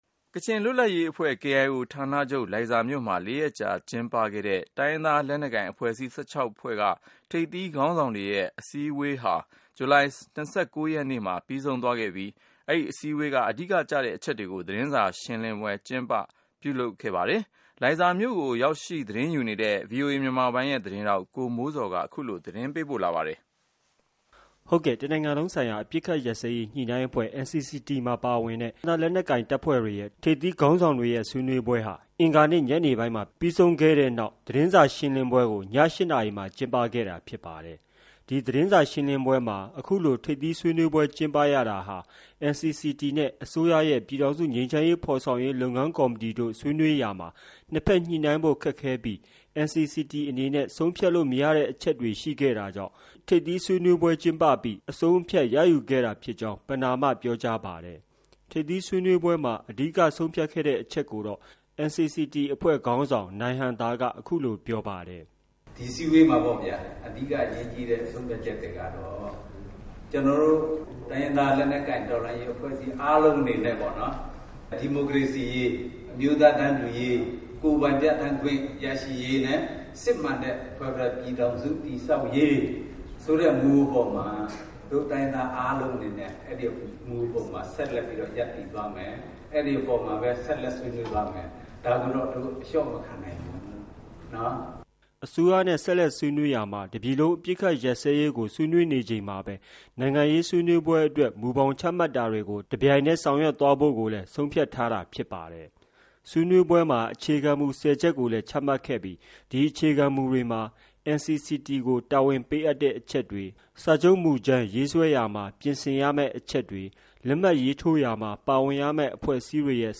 လိုင်ဇာသတင်းစာရှင်းလင်းပွဲ